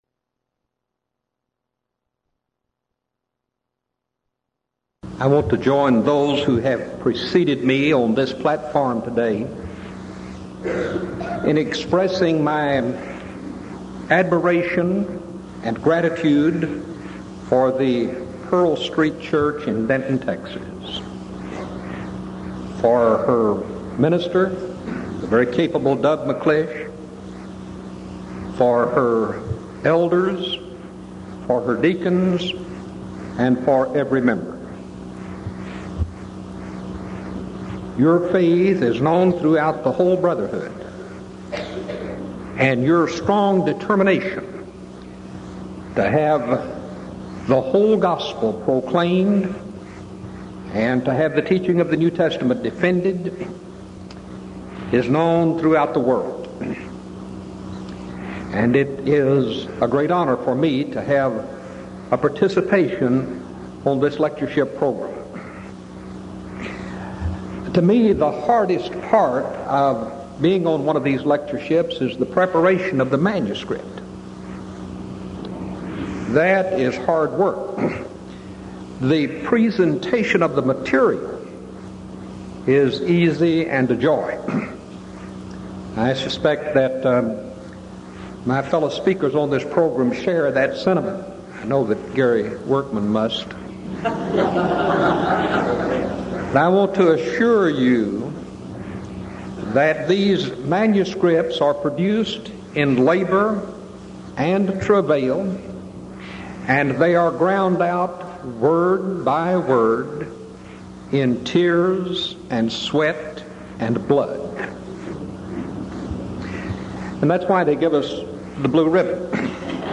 Event: 1986 Denton Lectures
If you would like to order audio or video copies of this lecture, please contact our office and reference asset: 1986Denton07